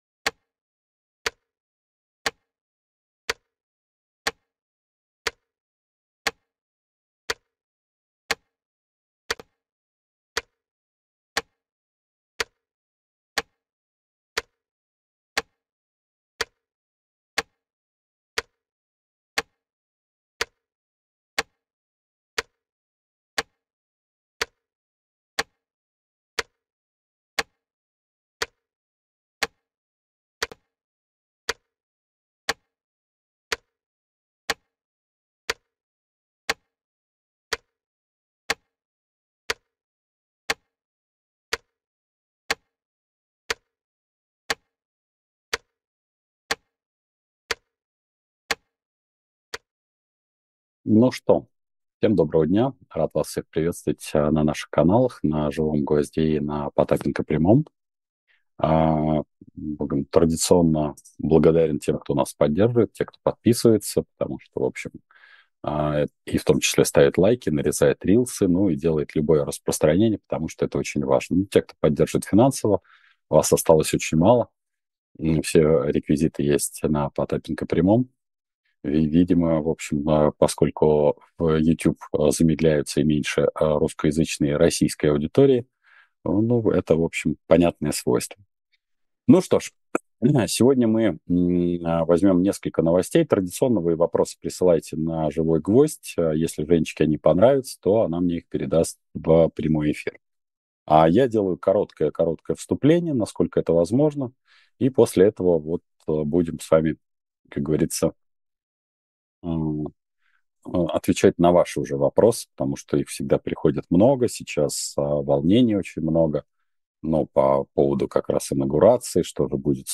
Эфир ведёт Дмитрий Потапенко